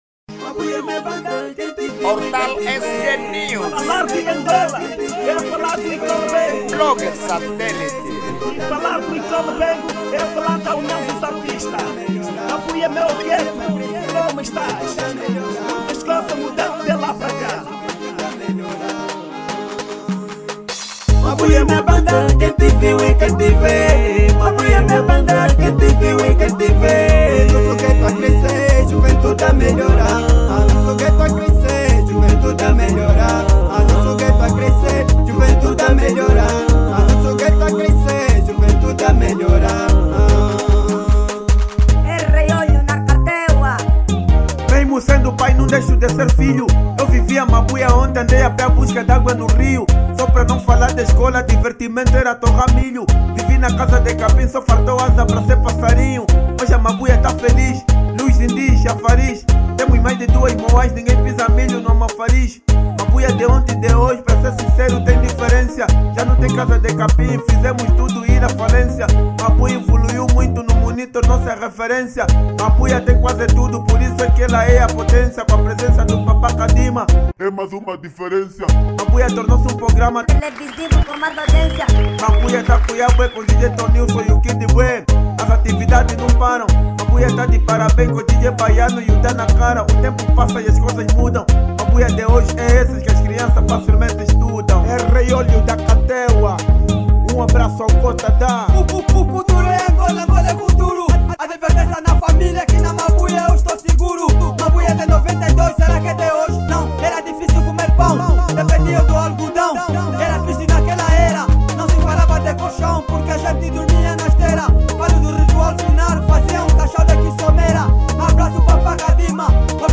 Género : Kuduro